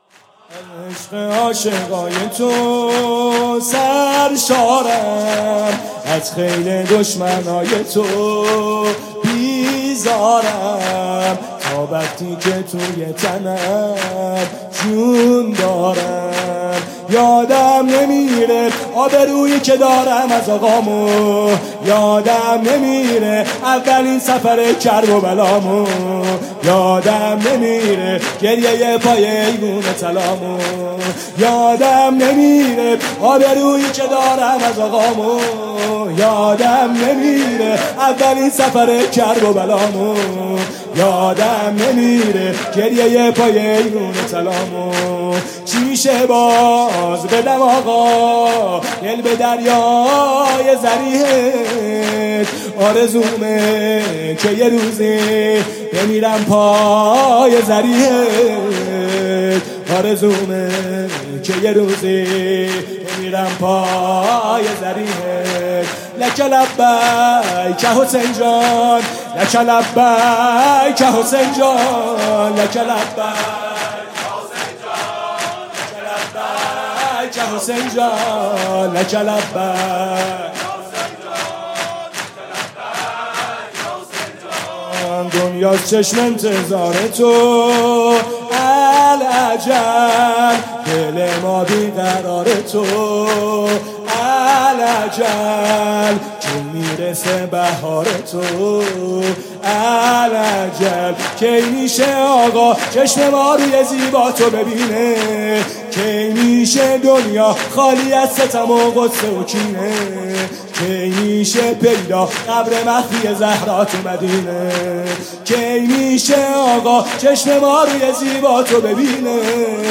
شب اول فاطمیه دوم ۱۴۰۴
music-icon زمزمه: از من زهرا روتو نگیر